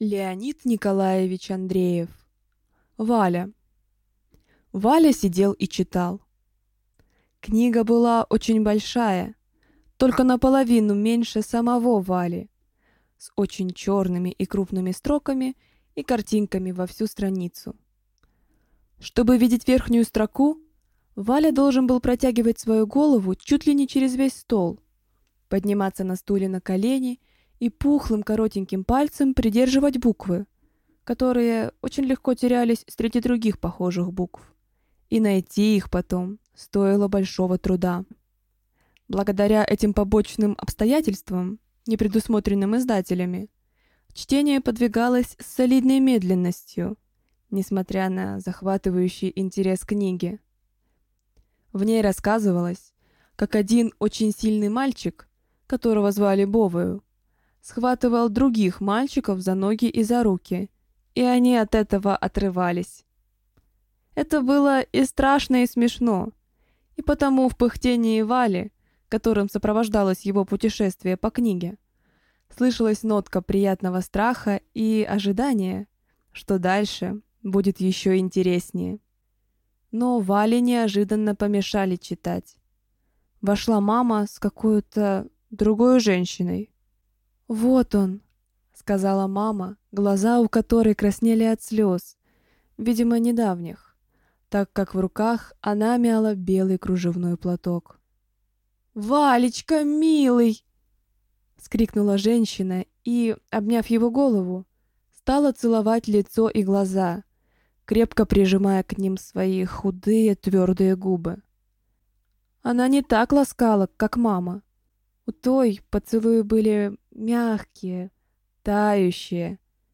Аудиокнига Валя | Библиотека аудиокниг
Прослушать и бесплатно скачать фрагмент аудиокниги